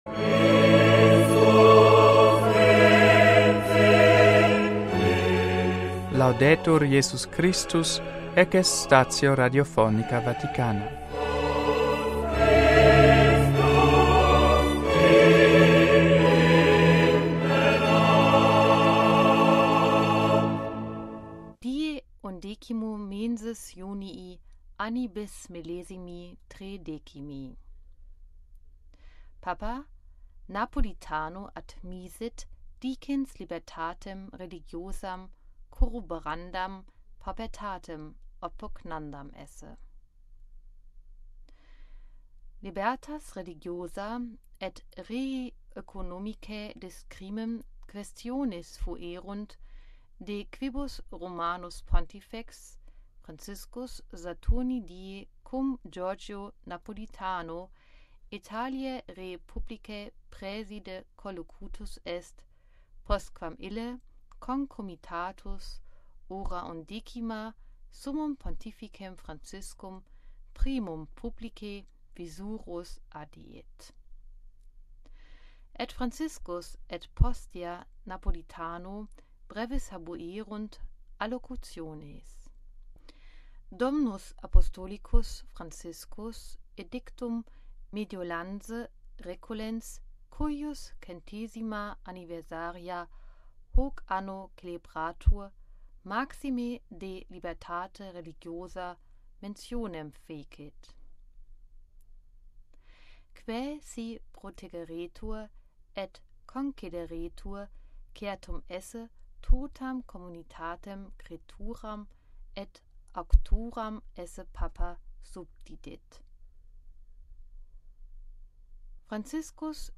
NUNTII STATIONIS RADIOPHONICAE VATICANAE PARTITIONIS GERMANICAE IN LINGUAM LATINAM VERSI